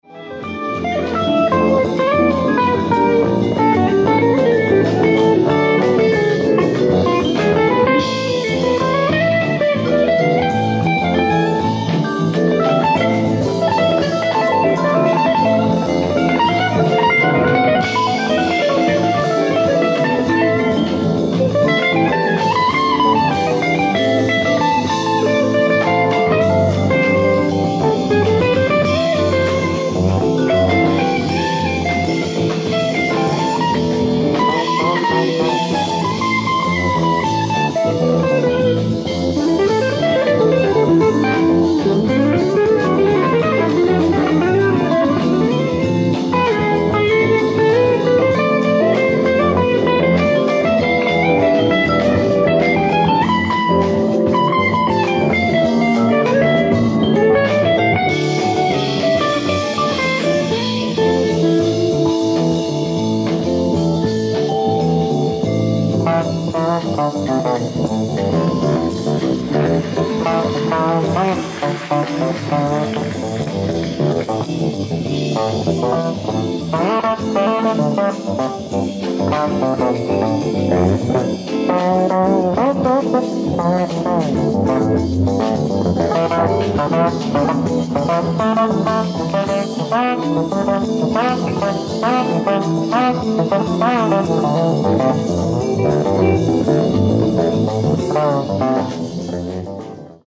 ライブ・アット・ハイデルベルク、ドイツ 12/06/1974
発掘された未発表サウンドボード音源！！
※試聴用に実際より音質を落としています。